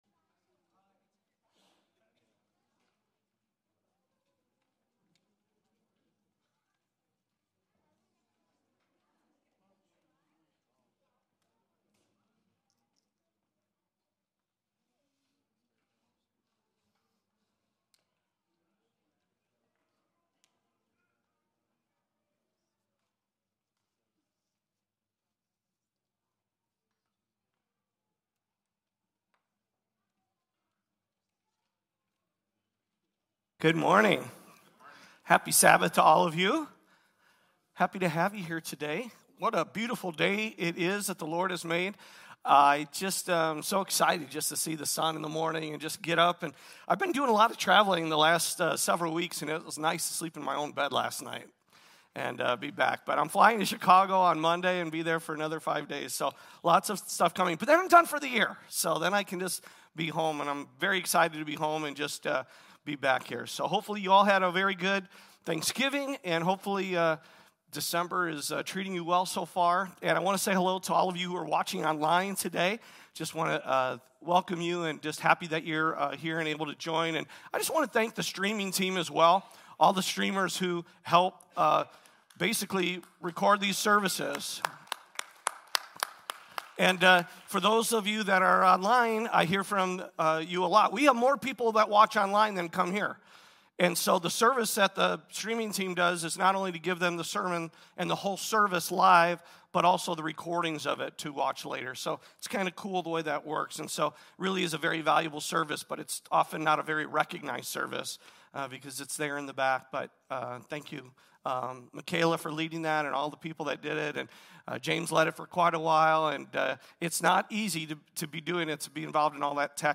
A Time to Build - Sabbath Christian Church | Rock Valley Christian Church